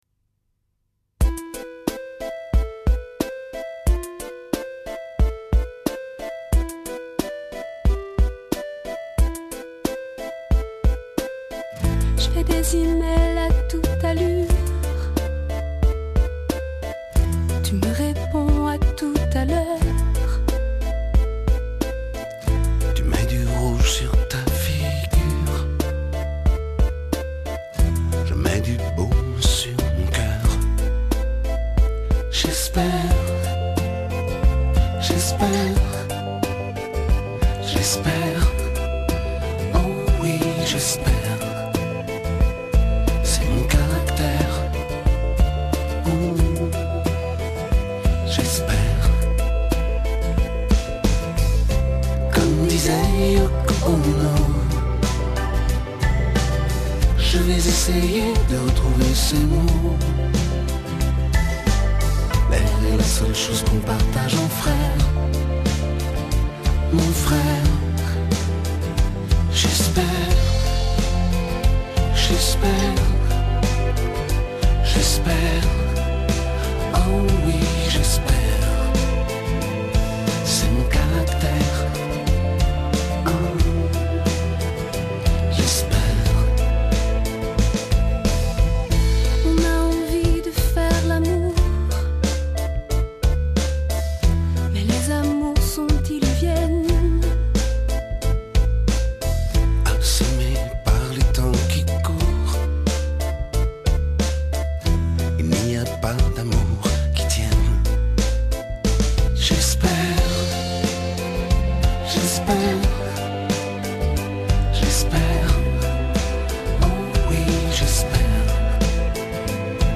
版本：原版CD VBR 320k